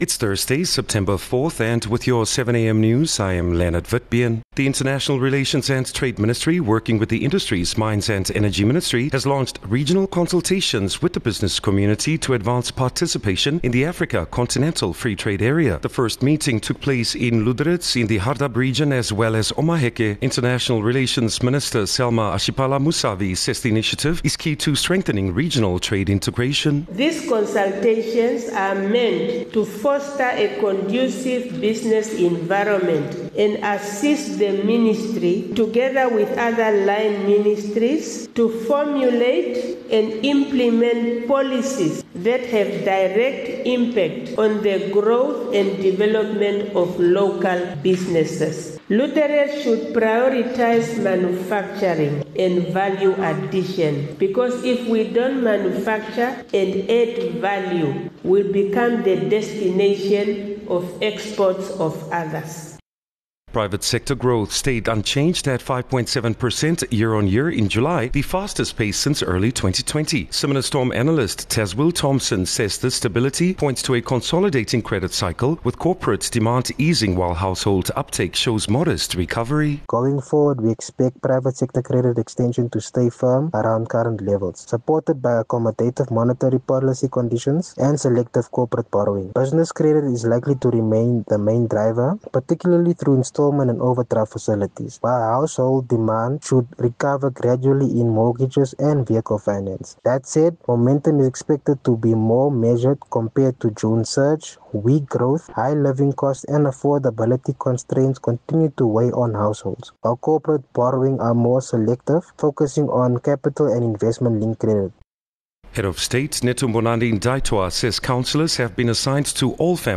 4 Sep 4 September-7am news